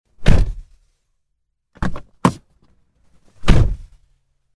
Звуки двери машины
Звук попытки закрыть дверь автомобиля со второго раза после неудачной первой попытки